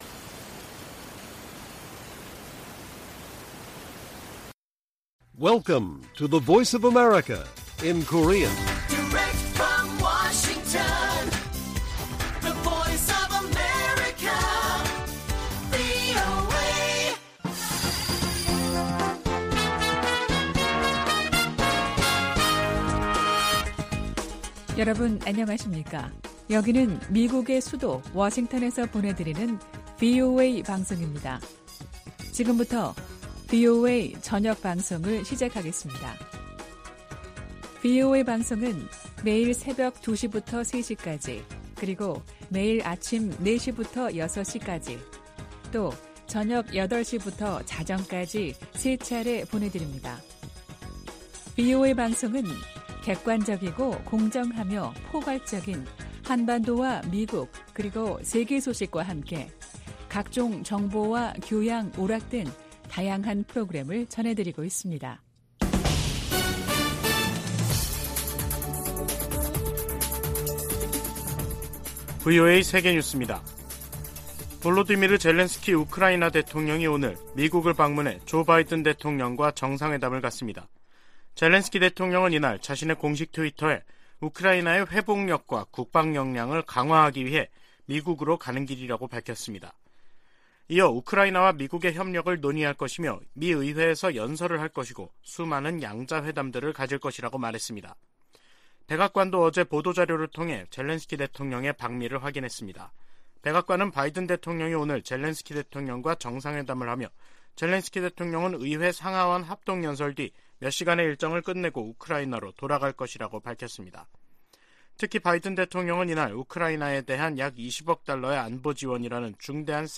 VOA 한국어 간판 뉴스 프로그램 '뉴스 투데이', 2022년 12월 21일 1부 방송입니다. 미 국무부는 북한의 7차 핵실험이 정치적 결단만 남았다며, 이를 강행시 추가 조치를 취하겠다고 밝혔습니다. 미국 국방부가 북한의 도발 억제를 위해 역내 동맹들과 긴밀하게 협력할 것이라고 거듭 밝혔습니다.